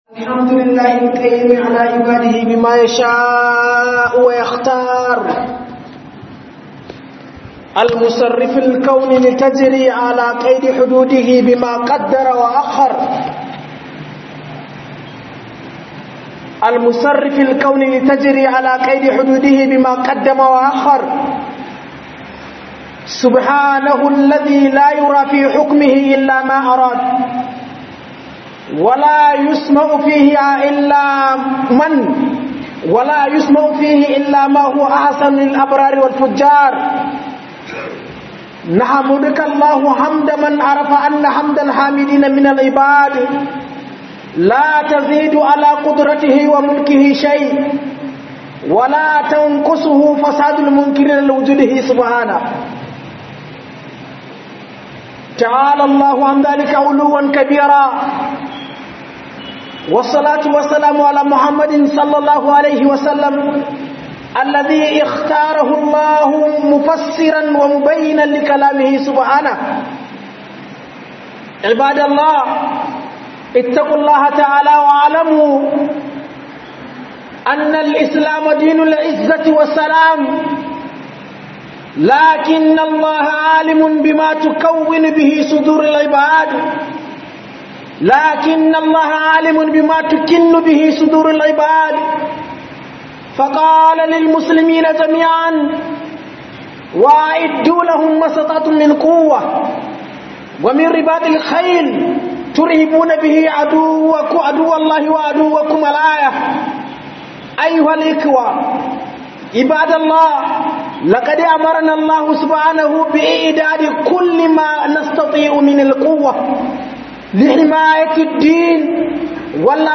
HUDUBA